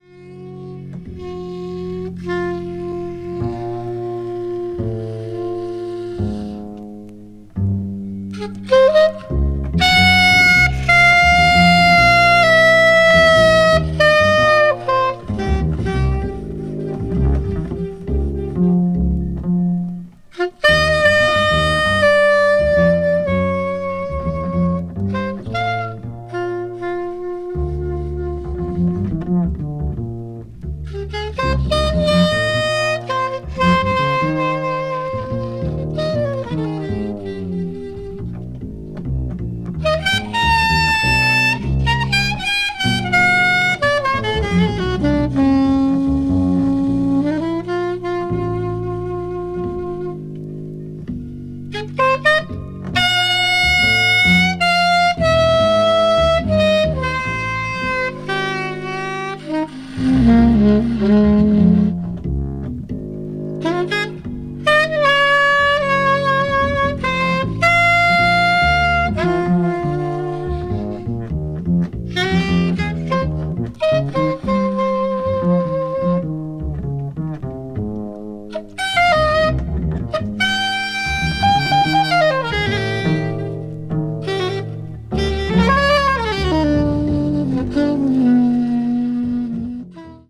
avant-jazz   free jazz   post bop   spiritual jazz